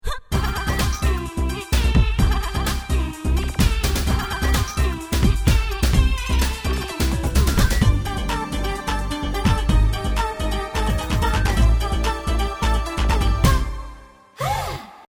Loopable